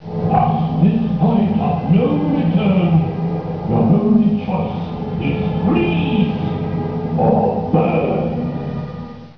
Sounds of Dueling Dragons
Some of the queue music has changed since these recordings were made